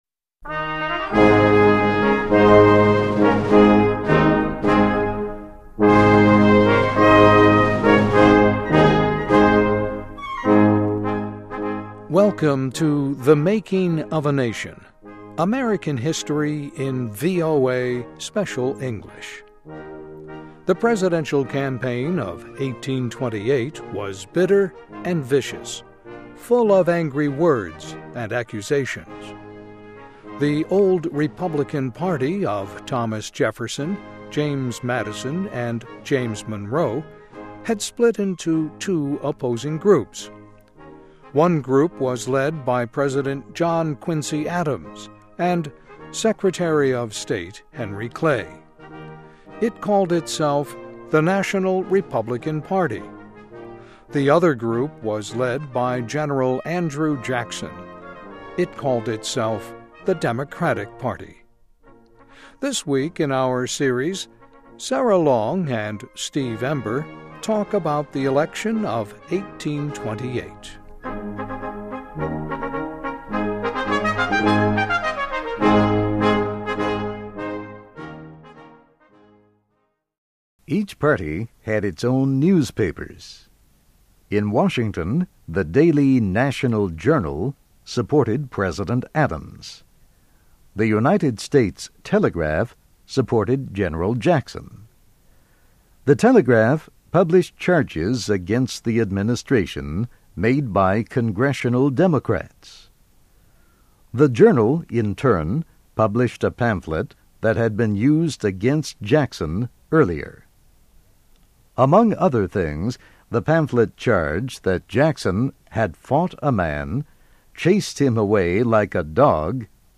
Listen and Read Along - Text with Audio - For ESL Students - For Learning English
Welcome to THE MAKING OF A NATION –- American history in VOA Special English.